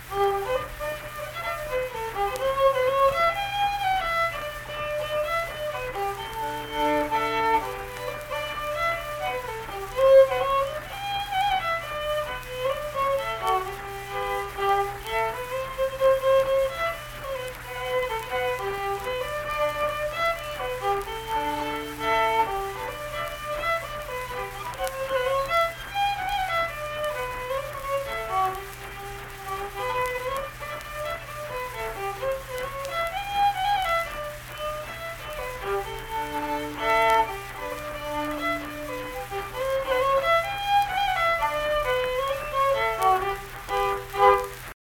Unaccompanied fiddle music performance
Verse-refrain 3(1).
Instrumental Music
Fiddle